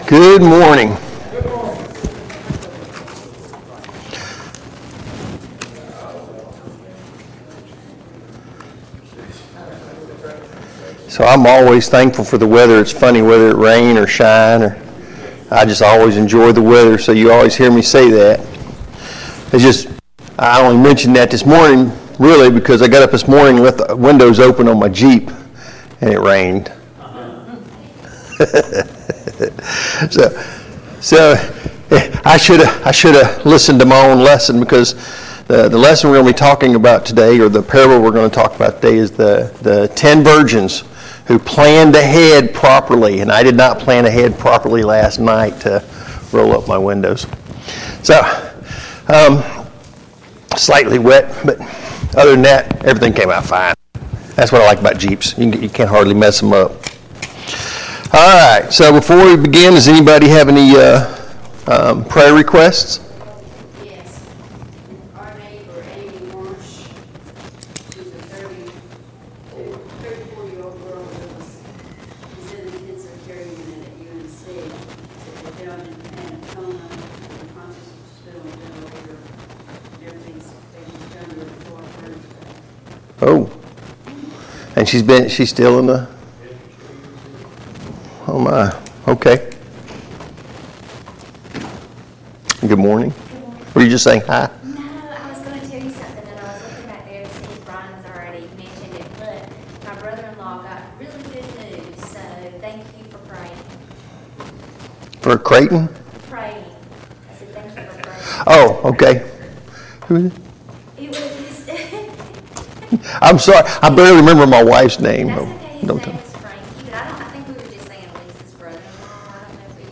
A Study on the Parables Service Type: Sunday Morning Bible Class Topics: The Parable of the Ten Virgins « 77.